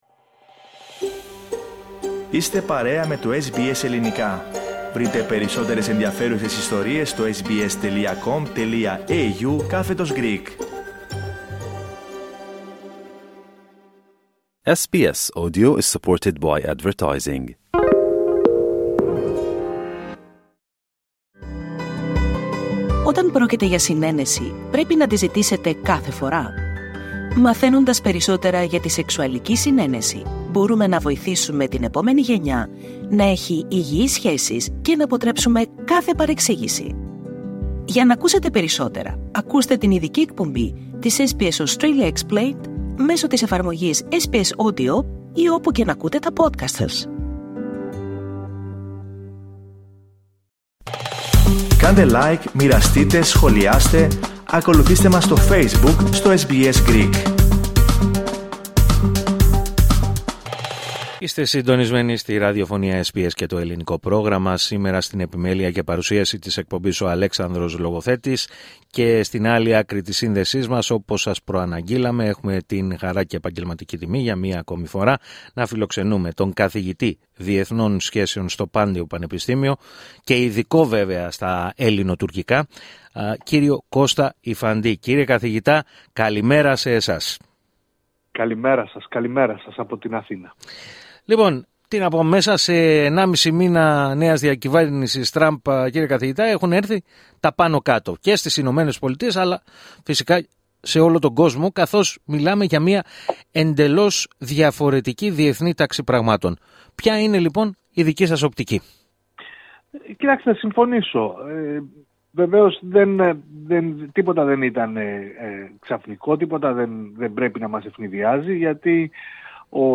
ο οποίος μίλησε στο Ελληνικό Πρόγραμμα της ραδιοφωνίας SBS.